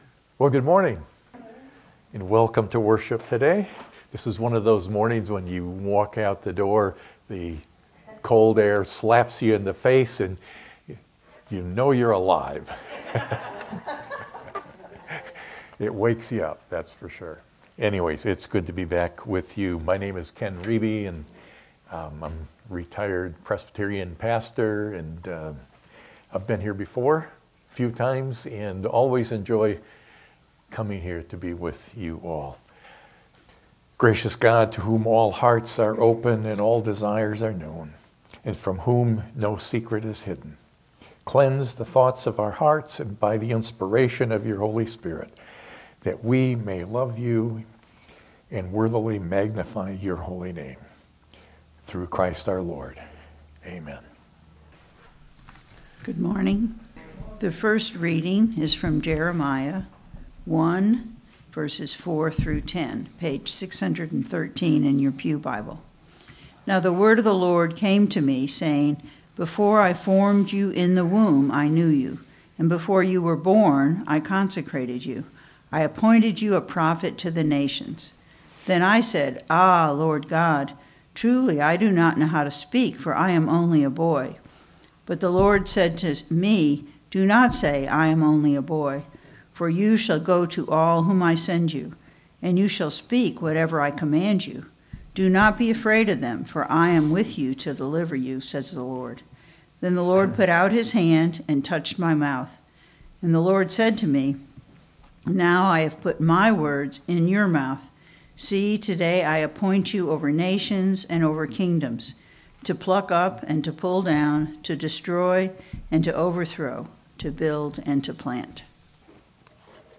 sermon-2.mp3